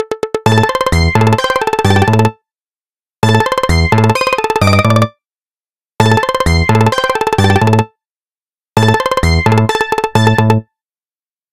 FunkyTempo1.ogg